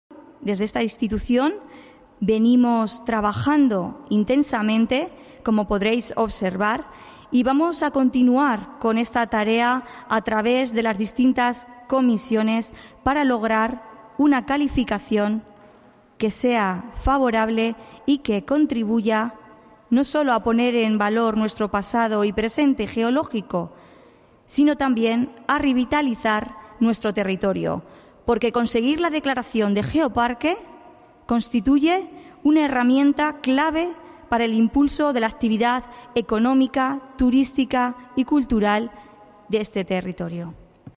Sonia González, vicepresidenta segunda de la Diputación de Ciudad Real